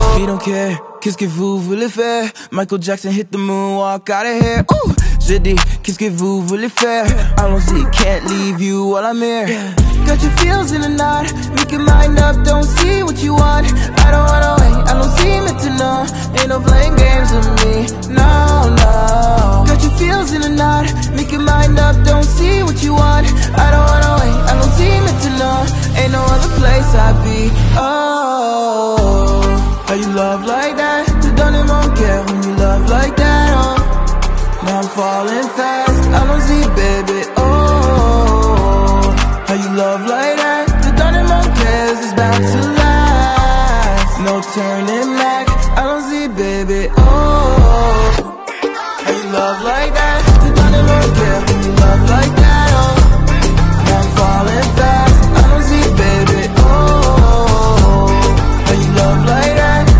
800 BPM